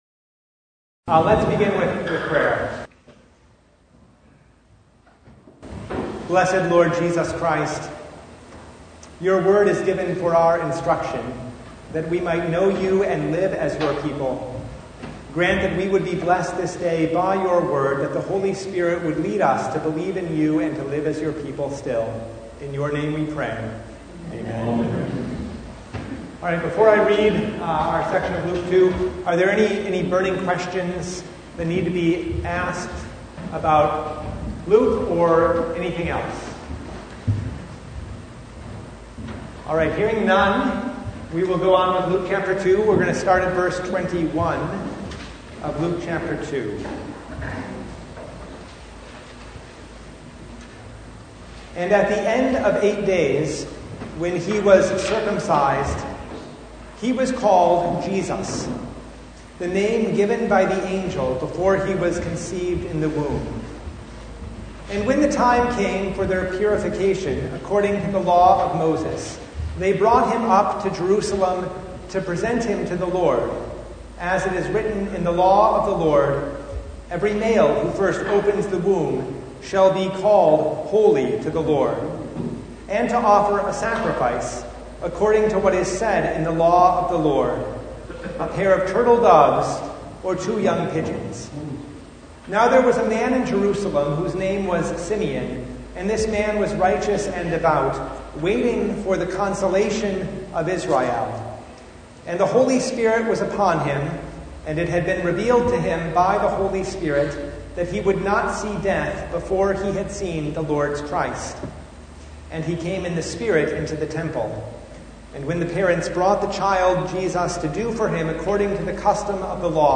Passage: Luke 2:21-52 Service Type: Bible Study Topics